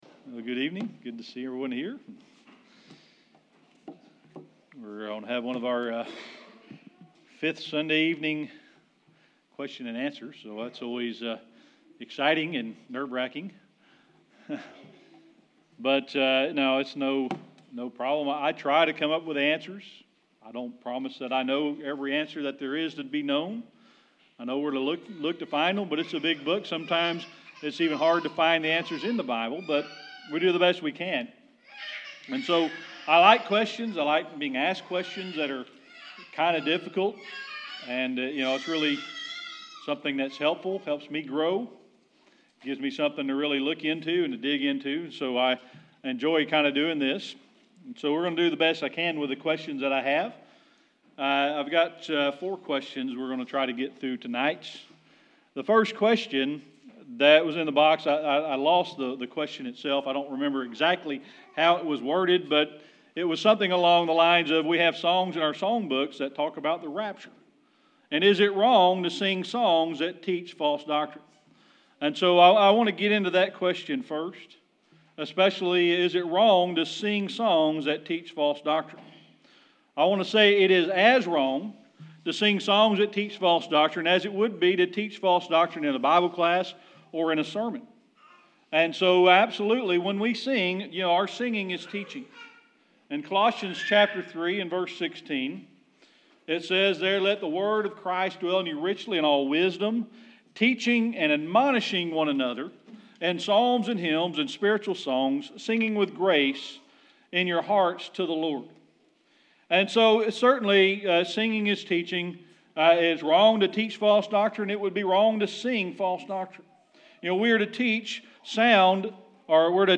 Tonight we're going to have one of our fifth Sunday evening question and answer sessions.
Sermon Archives Service Type: Sunday Evening Worship Tonight we're going to have one of our fifth Sunday evening question and answer sessions.